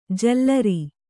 ♪ jallari